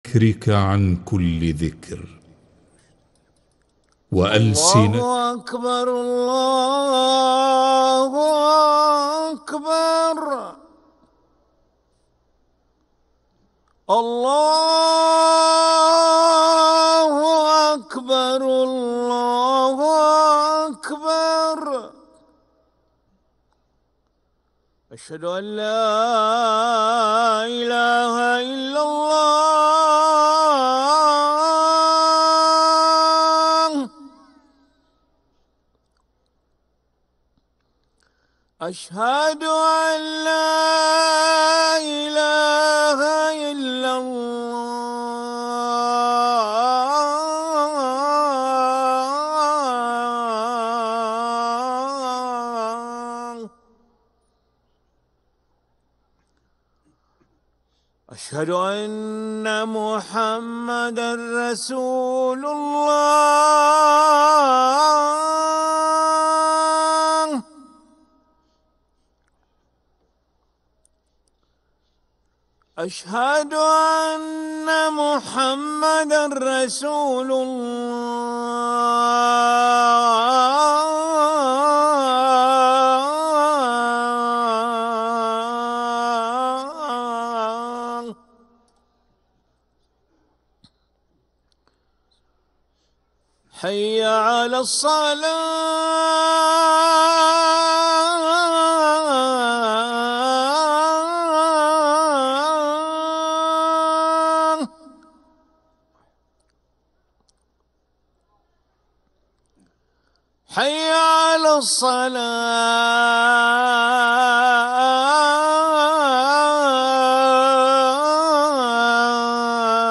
أذان العشاء للمؤذن علي ملا الخميس 11 جمادى الآخرة 1446هـ > ١٤٤٦ 🕋 > ركن الأذان 🕋 > المزيد - تلاوات الحرمين